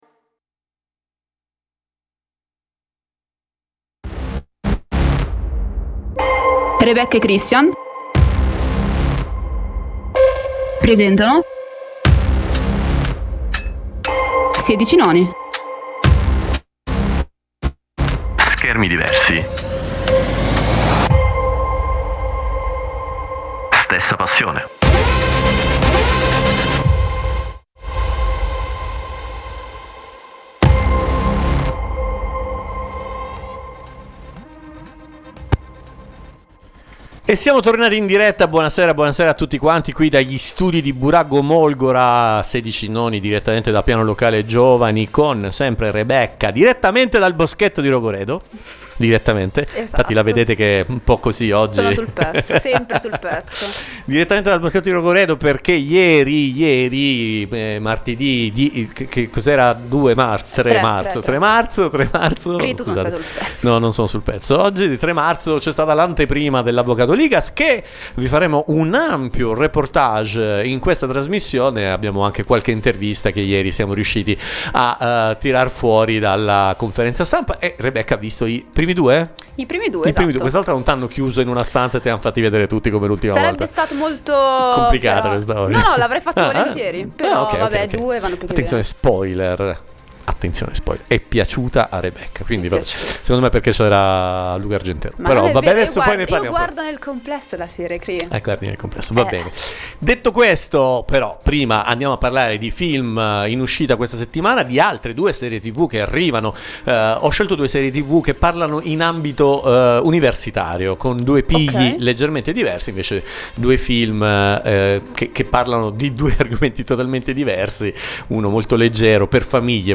Oltre all’analisi della serie, che vede Luca Argentero nel ruolo di un penalista nella Milano notturna, ascolteremo le testimonianze dirette degli attori raccolte sul red carpet, per approfondire la genesi di questo nuovo legal drama italiano.